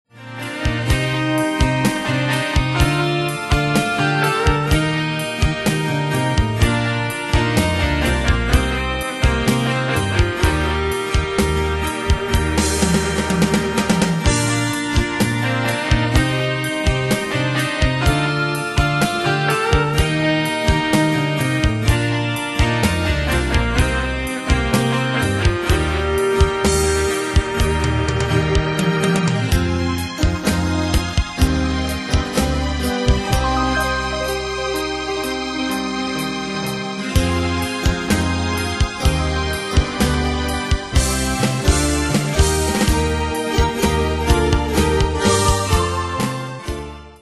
Style: Rock Année/Year: 1972 Tempo: 76 Durée/Time: 4.48
Danse/Dance: Rock Cat Id.
Pro Backing Tracks